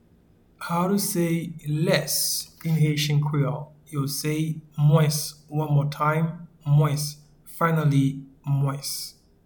Pronunciation and Transcript:
Less-in-Haitian-Creole-Mwens.mp3